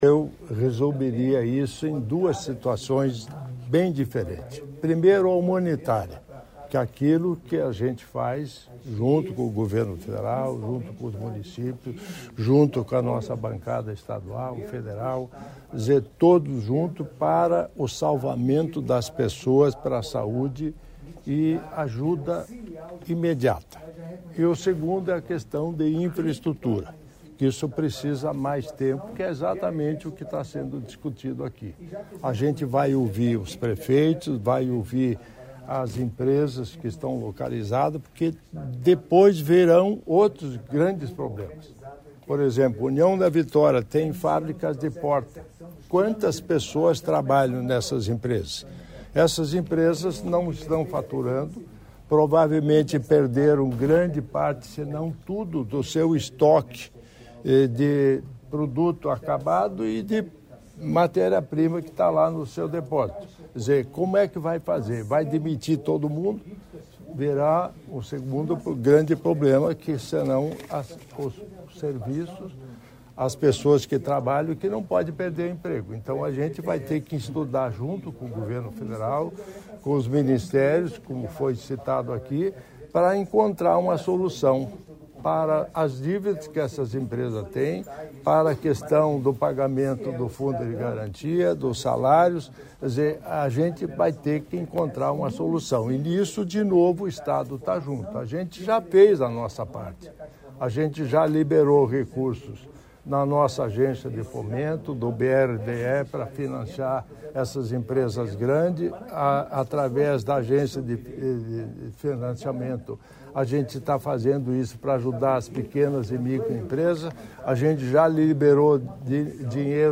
Sonora do vice-governador Darci Piana sobre a visita junto com o Ministro da Integração à União da Vitória